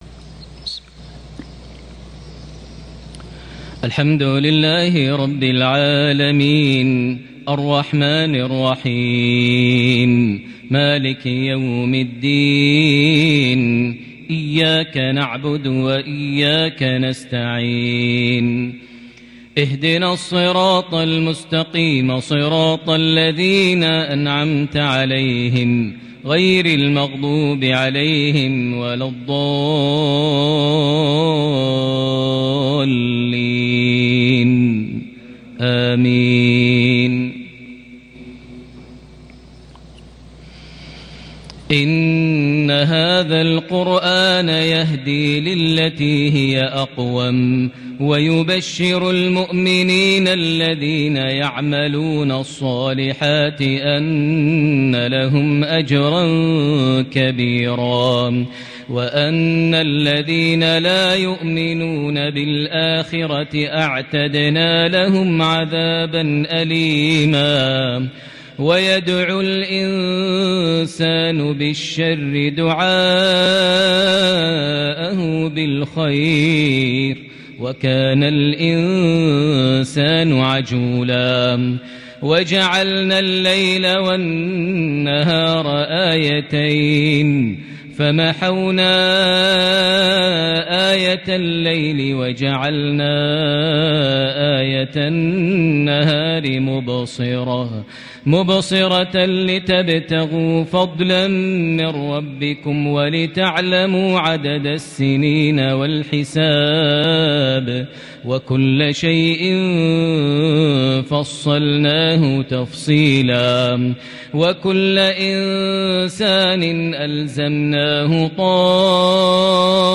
تلاوة بديعة من سورة الإسراء (9- 19) | مغرب 11 جمادى الأول 1442هـ > 1442 هـ > الفروض - تلاوات ماهر المعيقلي